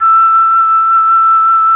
RED.FLUT1 34.wav